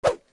arrowrelease.217d0b76cc62df3dec5d.mp3